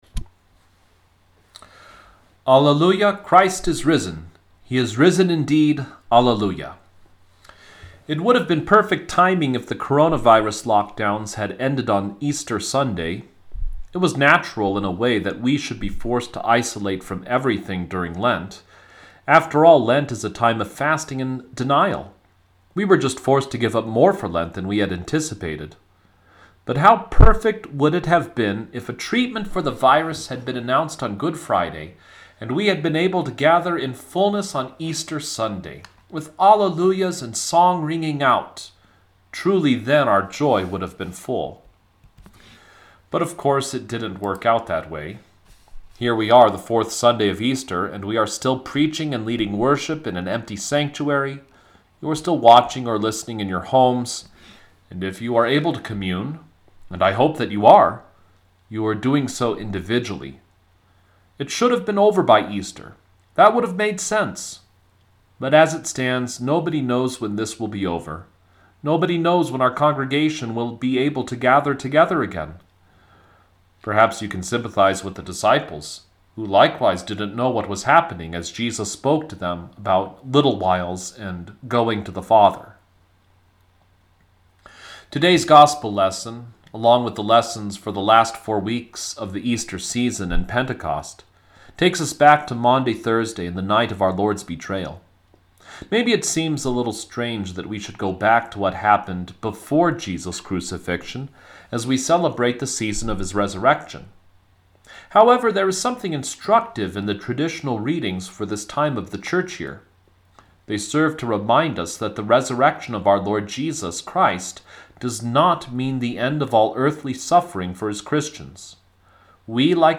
The Fourth Sunday of Easter